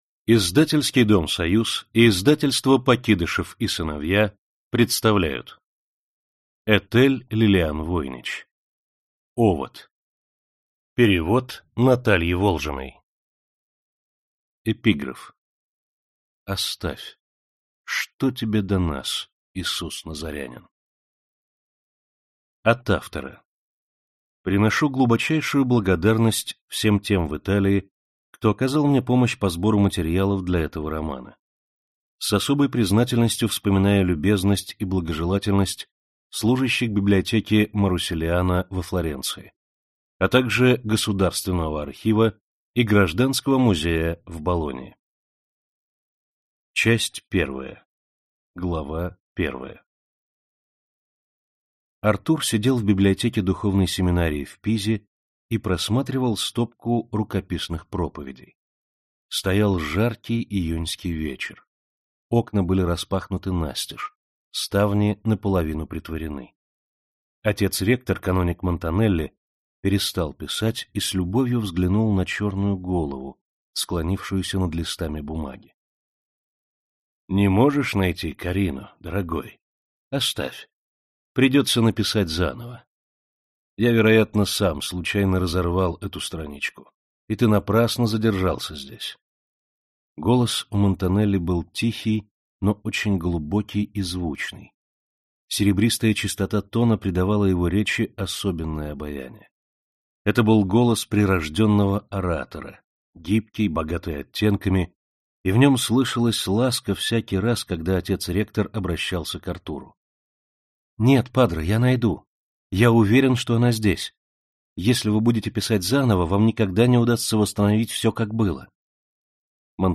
Аудиокнига Овод | Библиотека аудиокниг